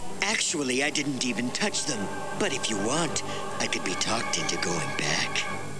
Vegeta's Voice.
Clips taken out of the Dragon Ball Z show.